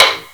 taiko-normal-hitclap.wav